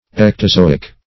ectozoic - definition of ectozoic - synonyms, pronunciation, spelling from Free Dictionary Search Result for " ectozoic" : The Collaborative International Dictionary of English v.0.48: Ectozoic \Ec`to*zo"ic\, a. (Zo["o]l.)
ectozoic.mp3